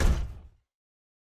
Footstep Robot Large 2_01.wav